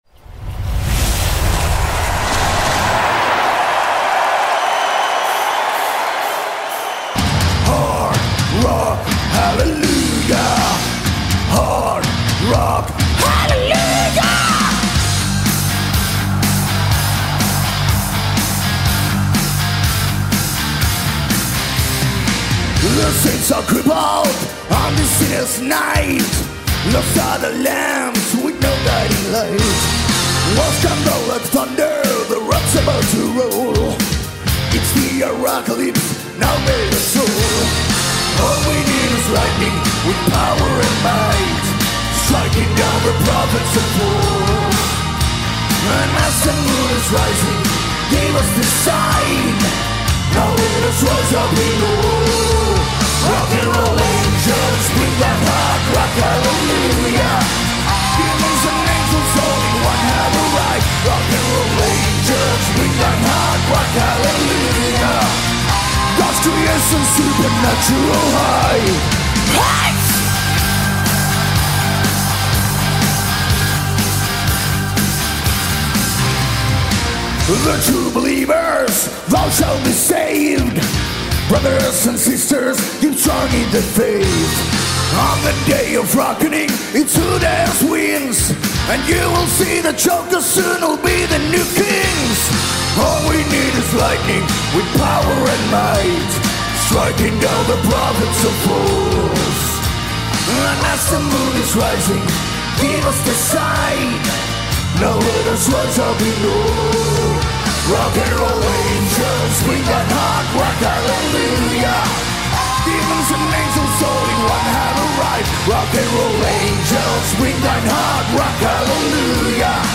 آنها در زمینه هارد راک و هوی متال فعالیت دارند.